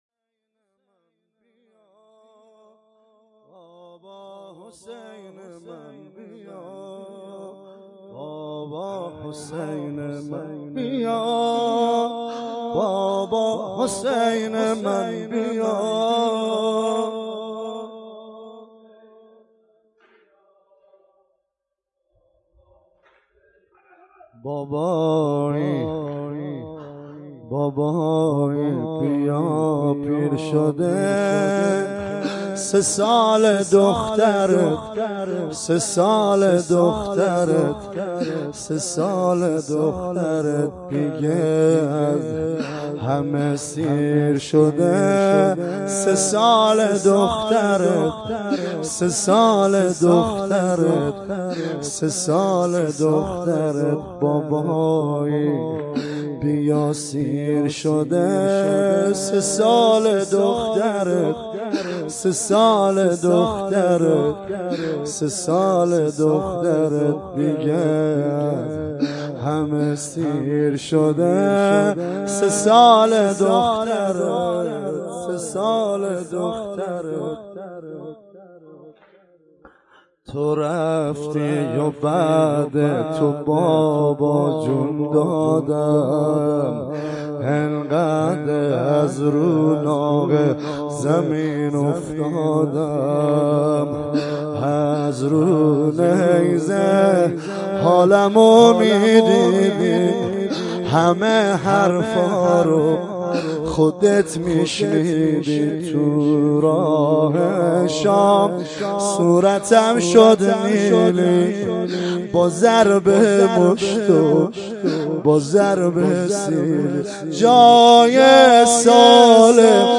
مداحی اربعین
بیا بابا حسین من _ واحد